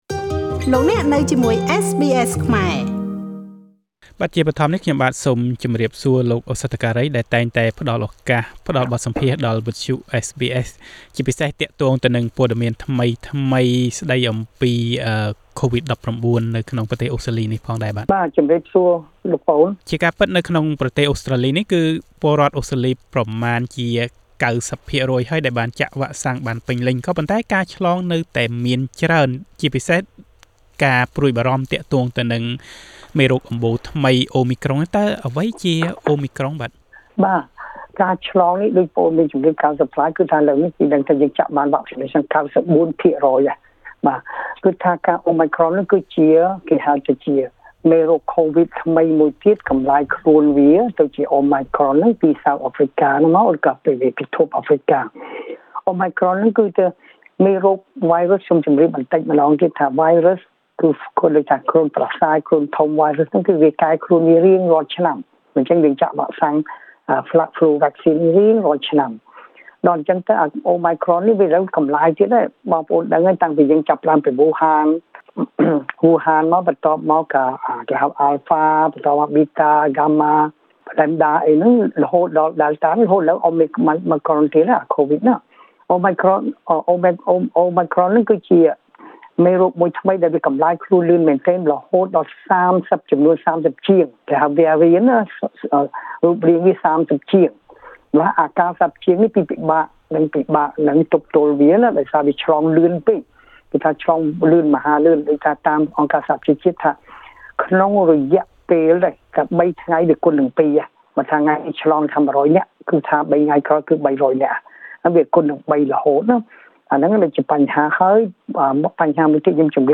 តើអ្វីទៅជាមេរោគបំប្លែងថ្មីអូមីក្រុង។ តើរដ្ឋាភិបាលអូស្ត្រាលីបានកែប្រែអ្វីខ្លះជាមួយនឹងការចាក់ដូសជំរុញដើម្បីទប់ទល់នឹងមេរោគនេះ តើពេលណាកុមារអាយុក្រោម 12ឆ្នាំអាចចាក់វ៉ាក់សាំងបាន។ តទៅនេះ សូមស្តាប់ការបកស្រាយរបស់លោក ឱសថការី